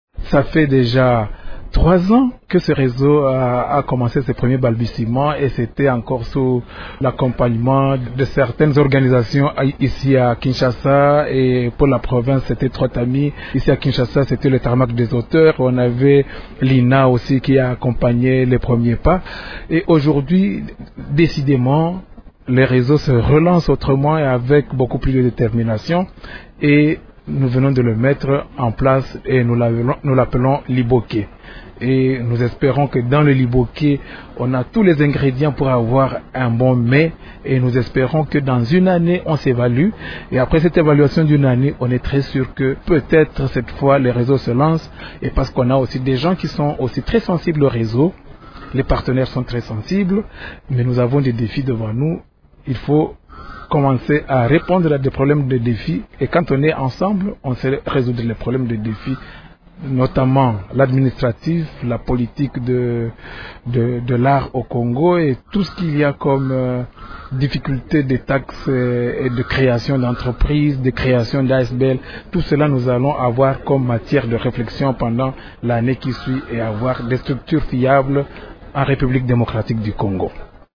Il s’entretient avec